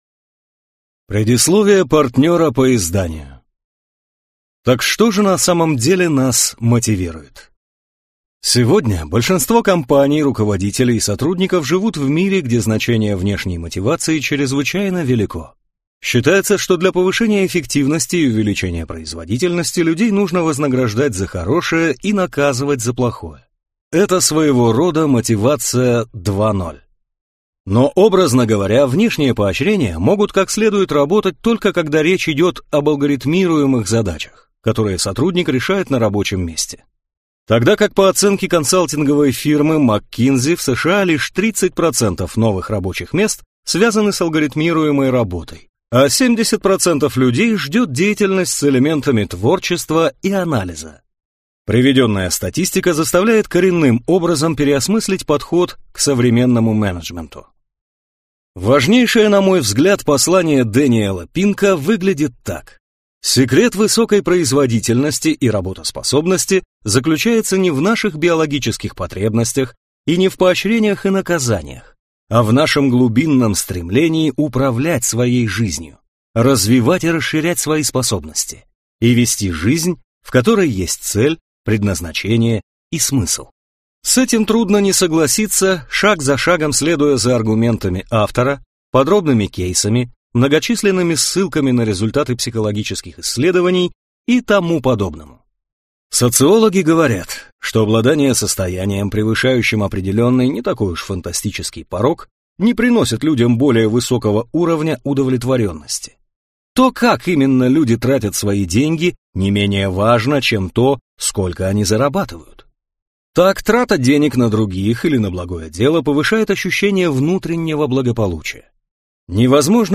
Аудиокнига Драйв: Что на самом деле нас мотивирует | Библиотека аудиокниг